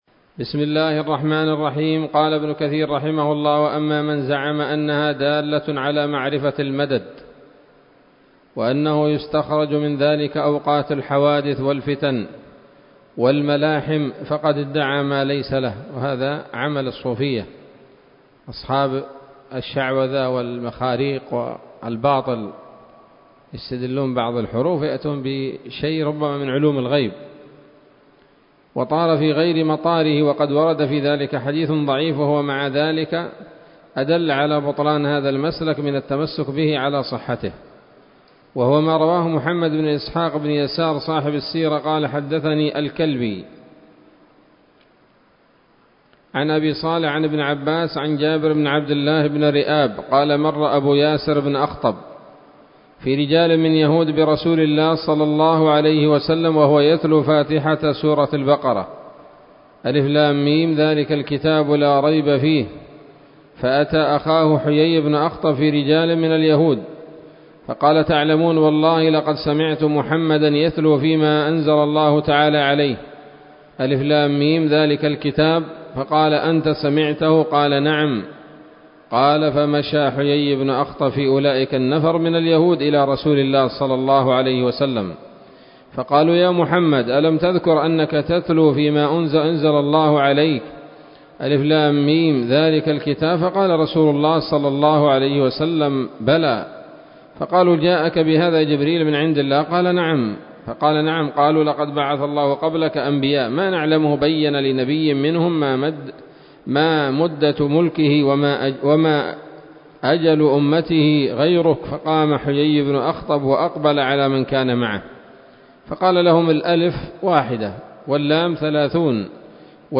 الدرس السابع من سورة البقرة من تفسير ابن كثير رحمه الله تعالى